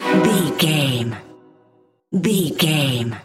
Aeolian/Minor
Fast
scary
tension
ominous
dark
eerie
strings